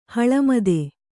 ♪ haḷamade